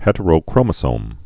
(hĕtə-rō-krōmə-sōm)